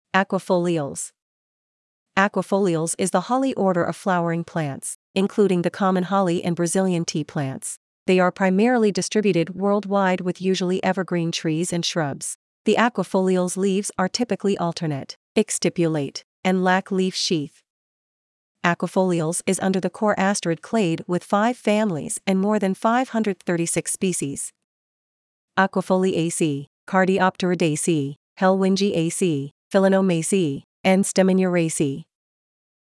Aquifoliales Pronunciation
Aquifoliales-Pronunciation.mp3